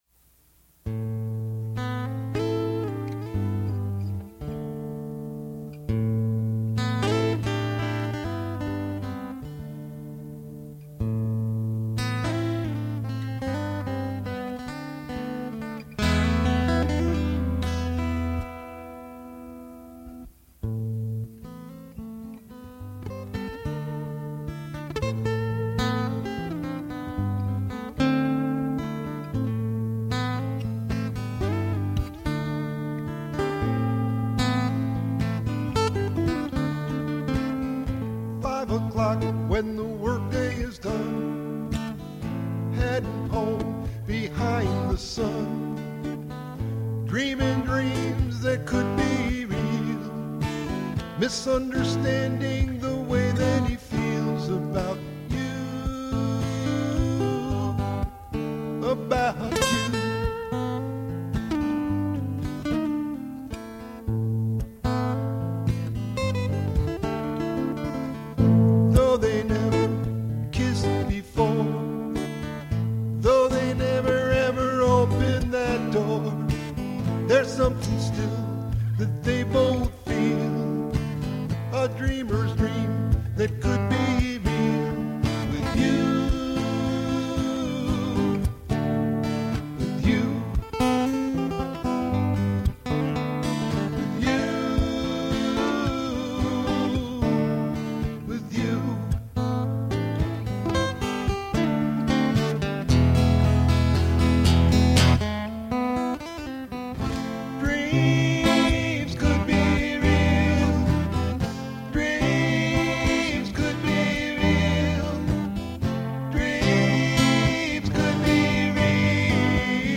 Acoustic & Electric Guitarist
Original Music: